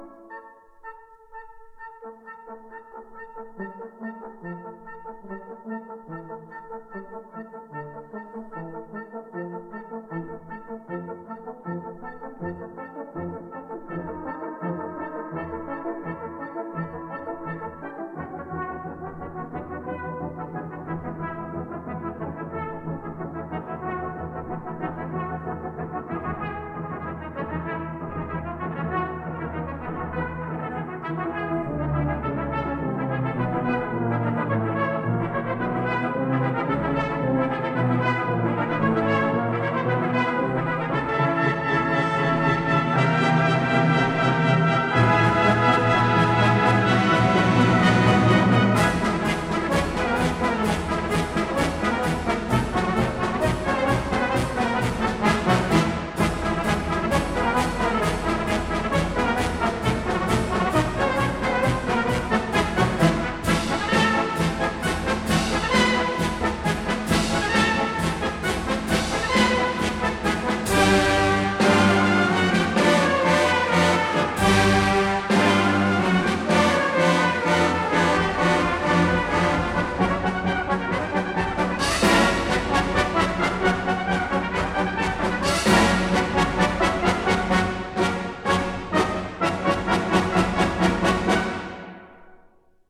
1959 stereo recording